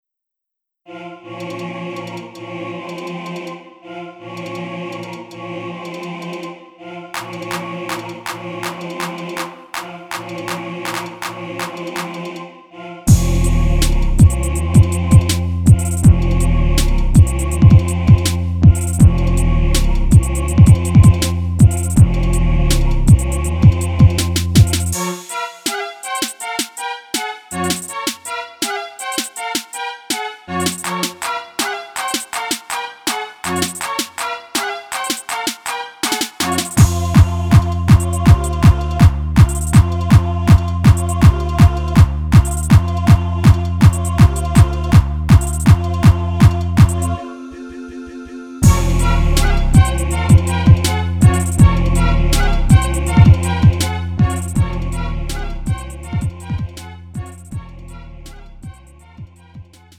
음정 -1키 3:27
장르 가요 구분 Lite MR